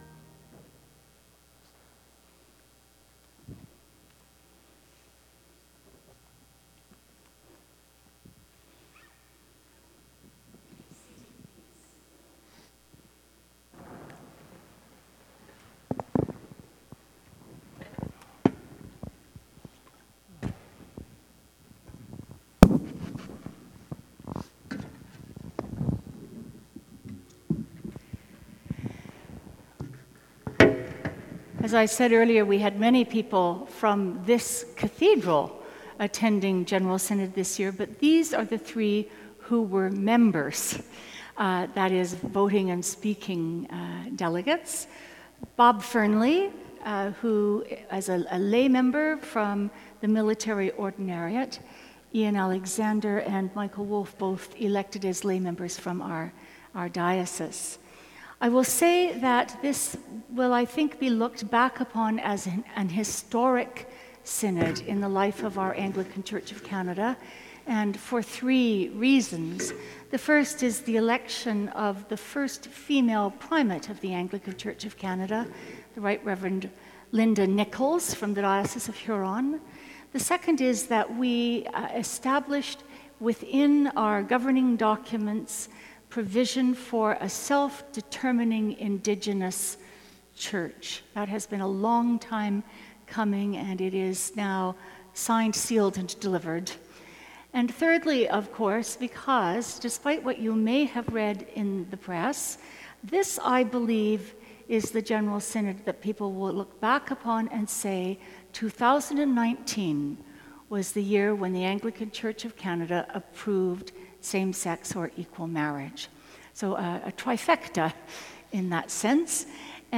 Sermon: 11.00 a.m. service